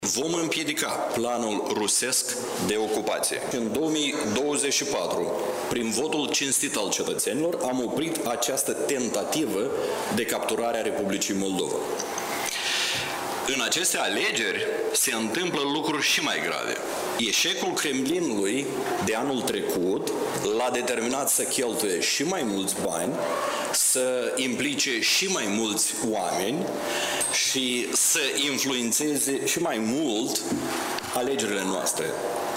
„Presiunea devine tot mai mare, iar probele în ceea ce privește acțiunile subversive ale Rusiei sunt tot mai numeroase”, a spus premierul Dorin Recean într-o conferință de presă.